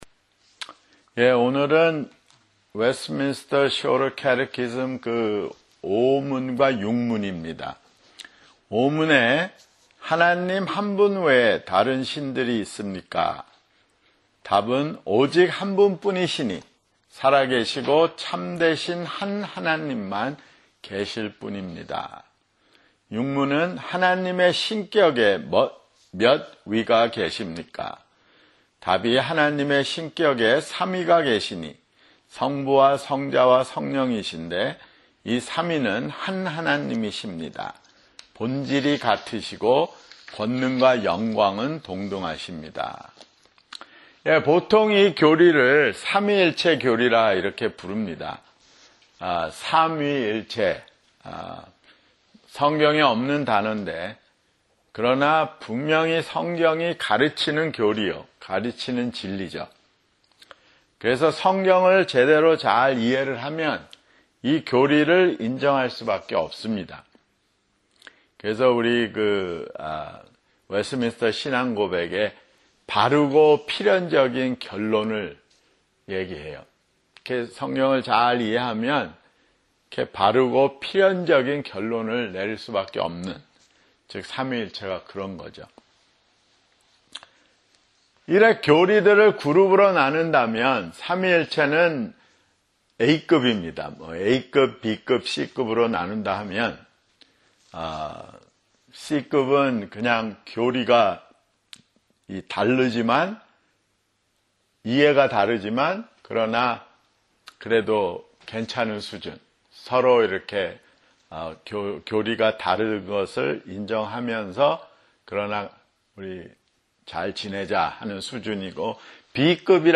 Wednesday, January 28, 2026 [성경공부] 소요리문답 2025 Q5-6 (5) 1부 소요리문답 2025 Q5-6 (5) 1부